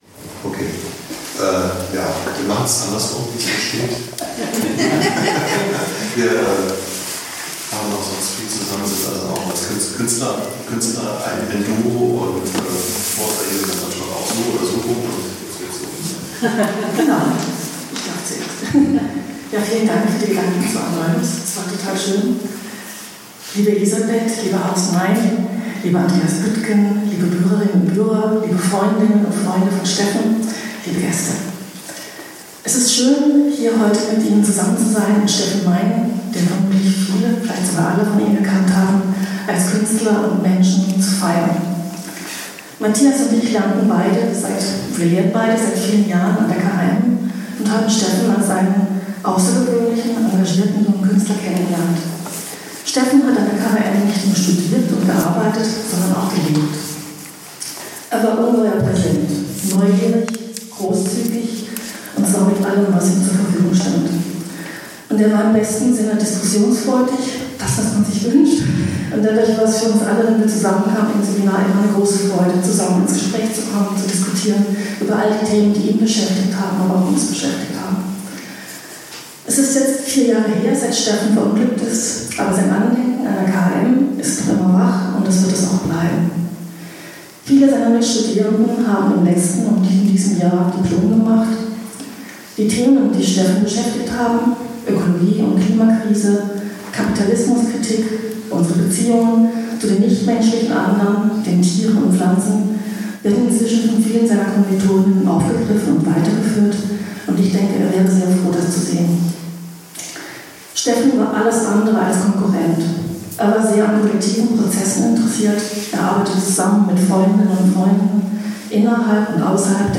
Grußworte